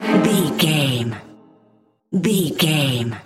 Sound Effects
Aeolian/Minor
Fast
tension
ominous
dark
haunting
eerie
strings